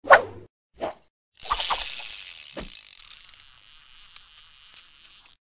TL_fishing_pole.ogg